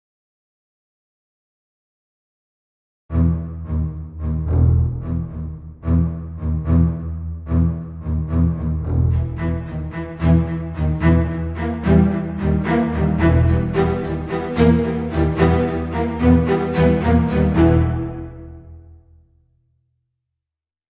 Download Movie teaser sound effect for free.
Movie Teaser